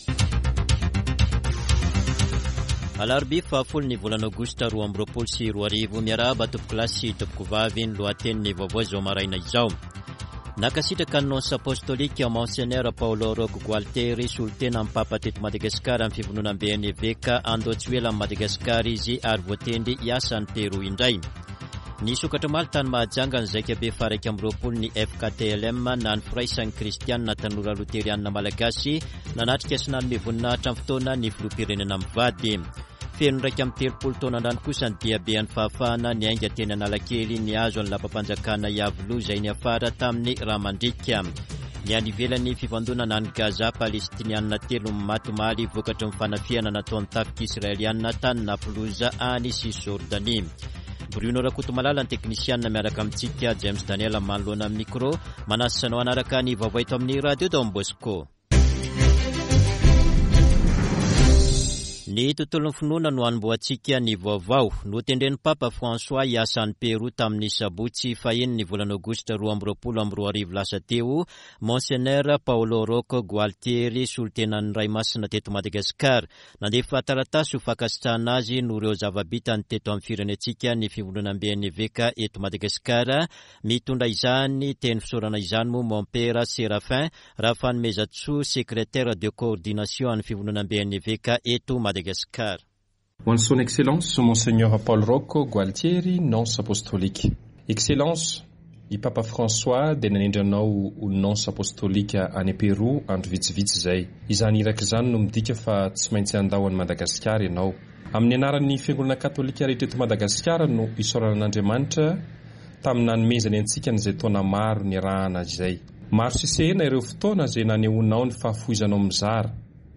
[Vaovao maraina] Alarobia 10 aogositra 2022